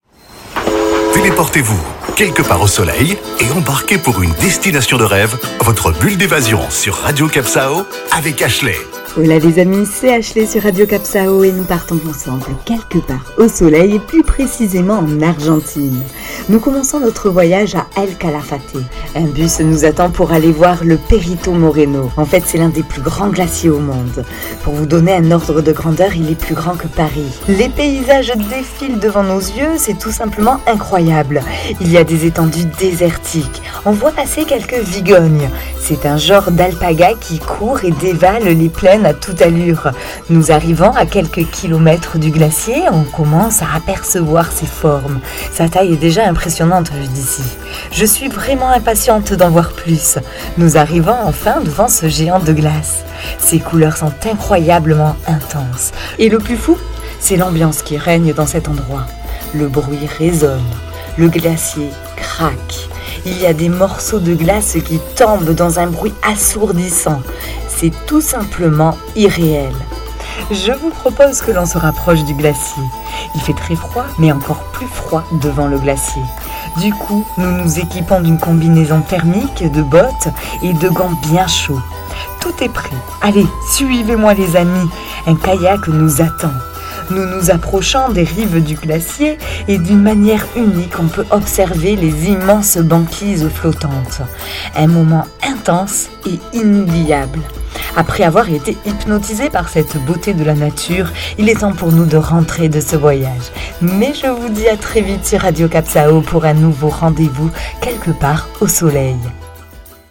Il y fait froid et l'ambiance sonore et visuelle est irréelle.